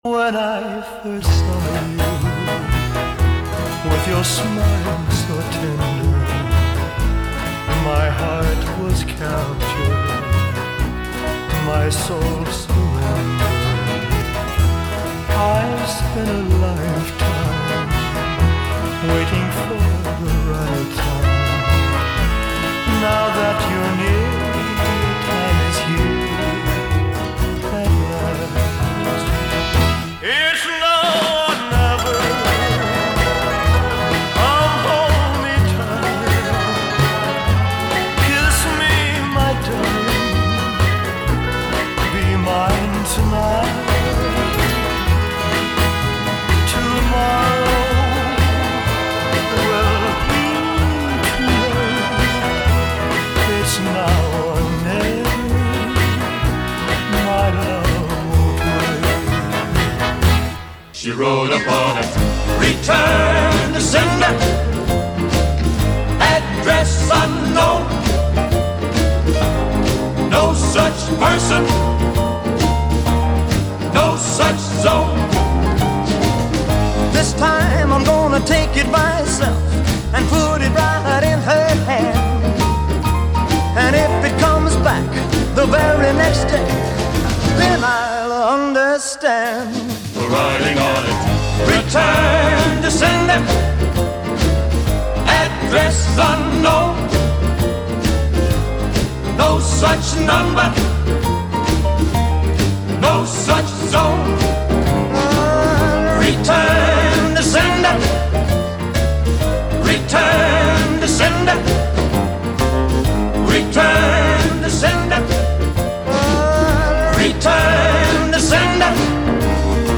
The clarity and "presence" of his voice is stunning!